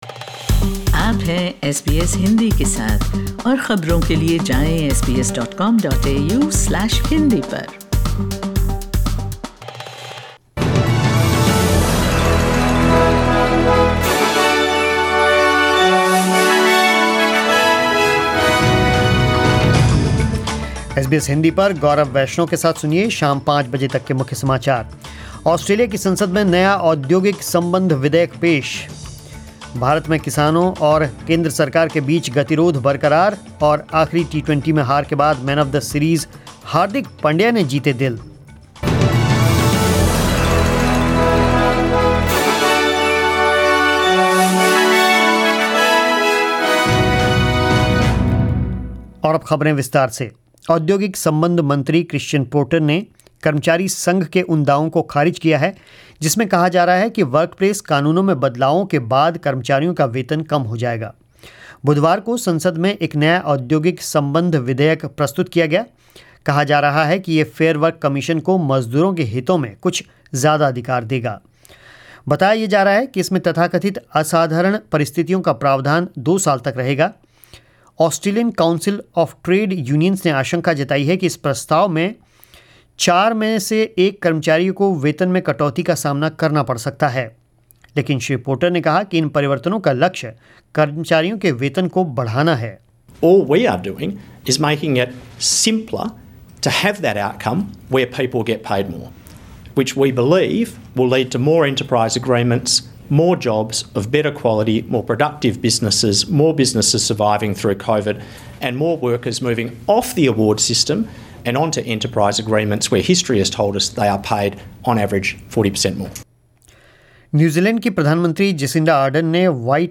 News in Hindi 09 December 2020